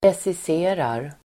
Uttal: [presis'e:rar]